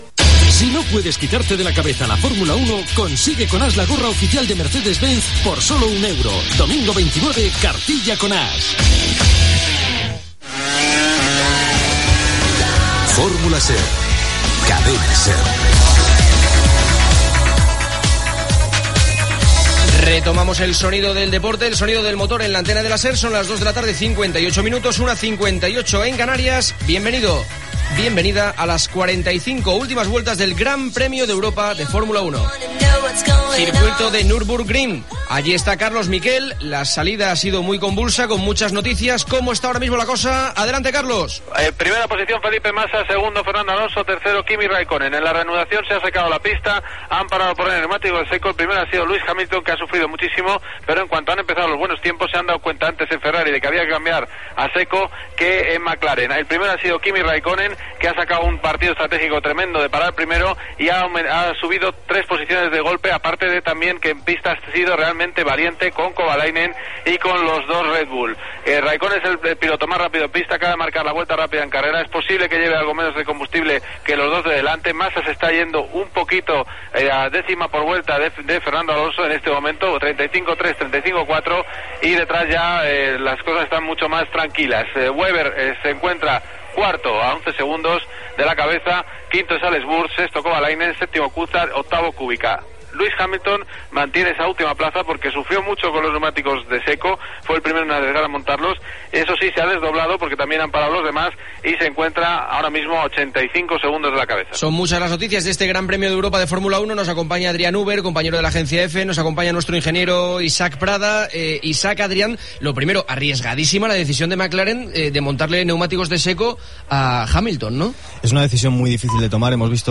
Careta del programa i retransmissió del Gran Premi d'Europa de Fórmula 1 des del circuit de Nürburgring. Informació de l'estat de la cursa i de les primeres voltes sota la pluja. Connexió amb l'Auditorio de Oviedo.
Gènere radiofònic Esportiu